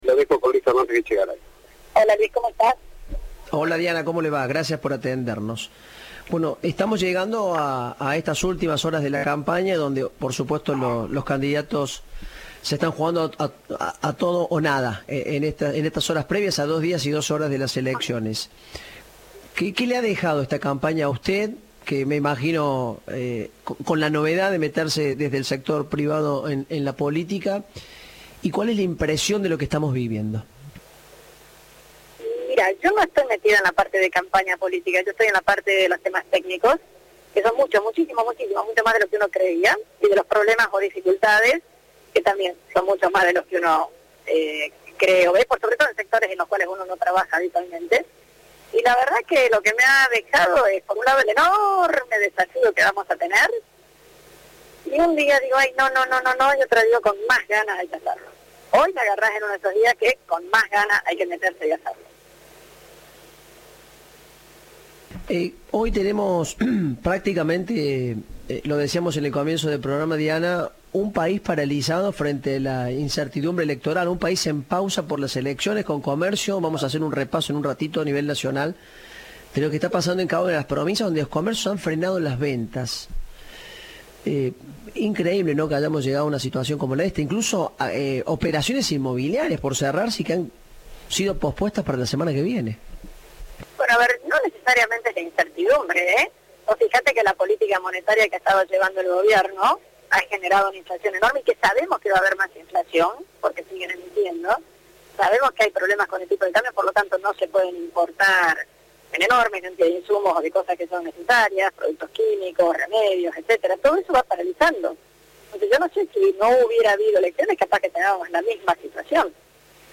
La economista referente de La Libertad Avanza habló con Cadena 3 y sembró un manto de duda respecto a la inflación y a la deuda externa argentina a días de las elecciones generales.